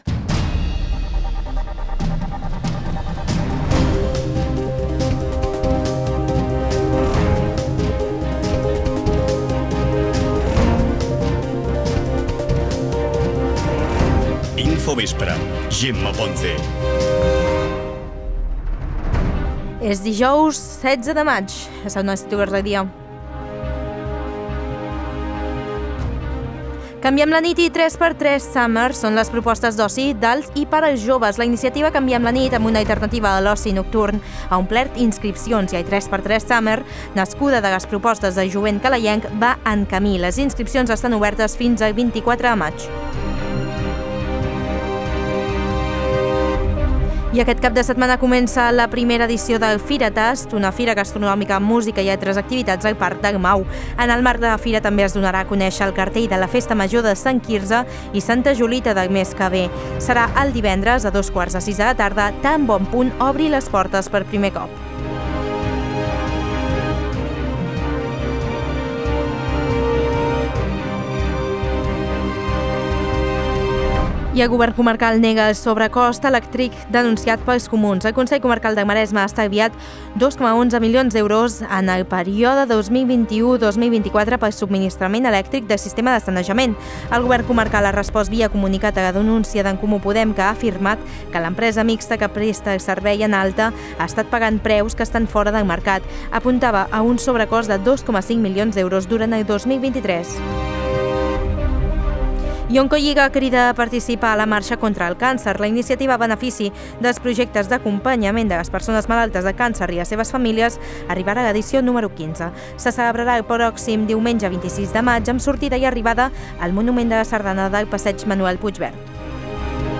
Notícies d'actualitat local i comarcal.